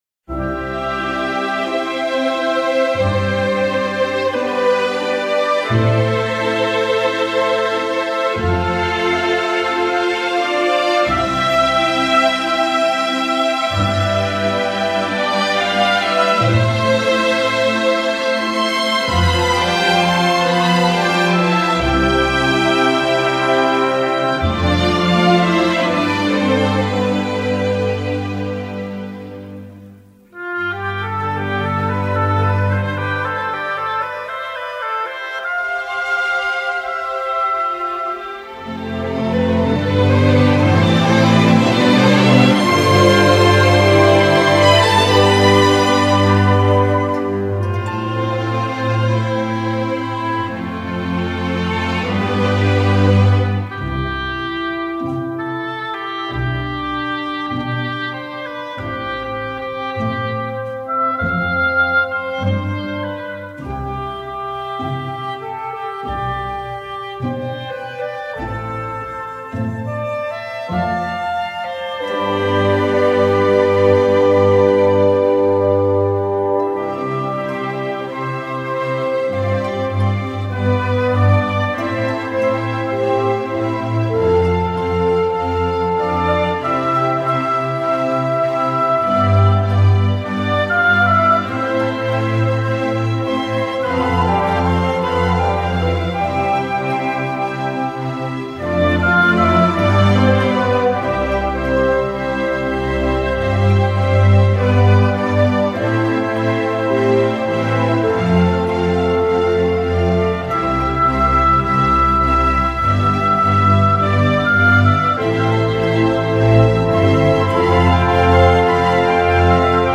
Instrumental version